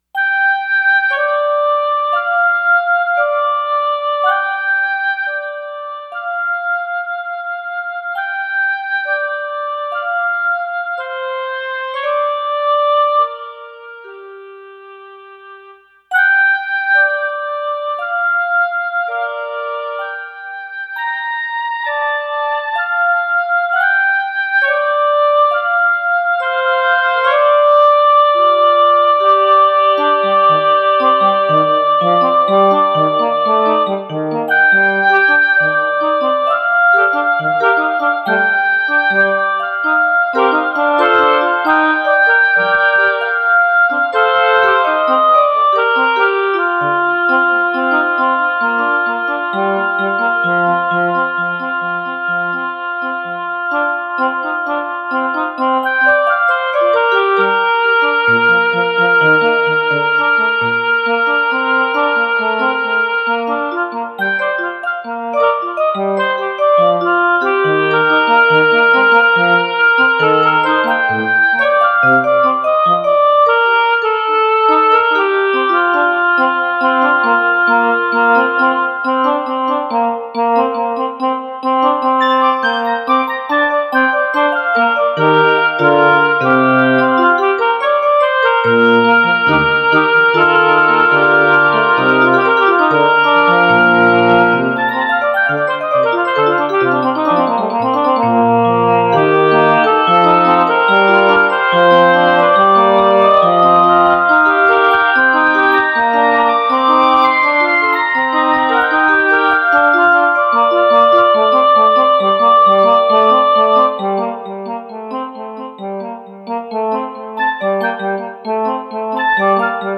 solo on Roland brand synthesizer.
home studio